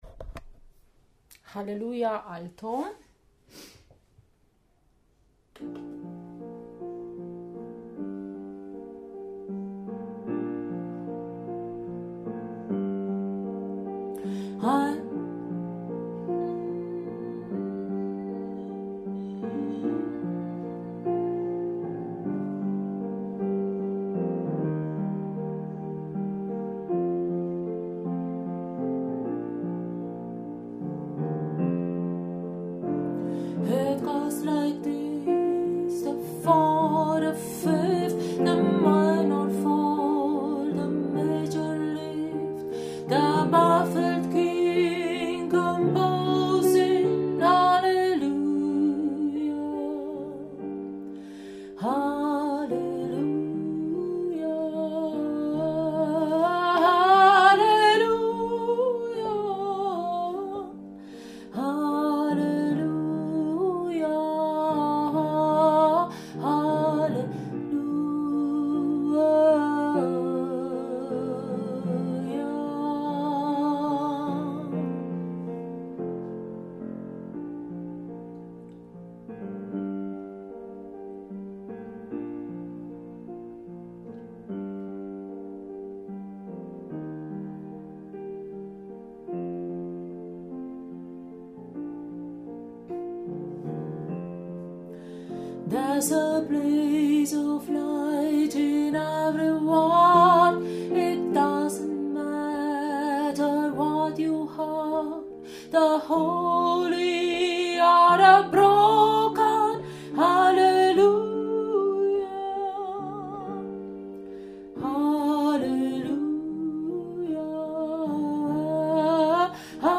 HallelujahCohen-Alto.mp3